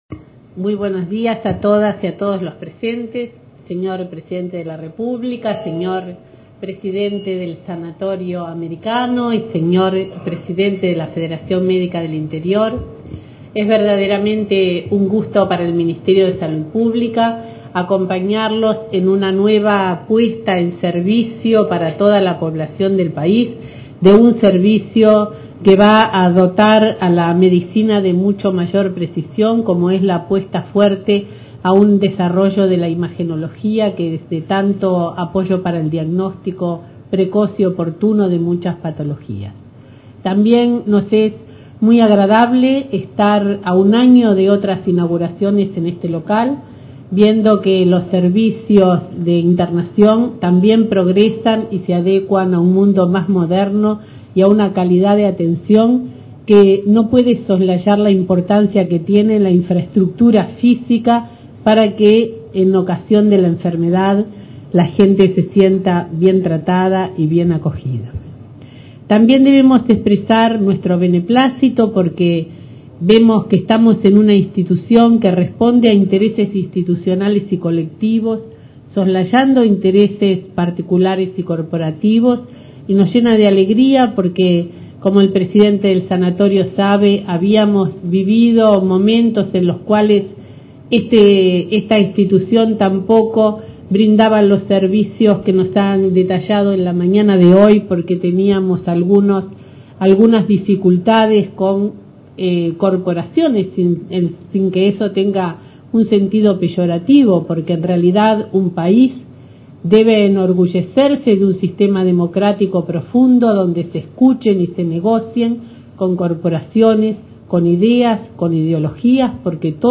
Palabras de la Ministra de Salud Pública, María Julia Muñoz, en la inauguración de un Servicio de Resonancia Magnética en el Sanatorio Americano.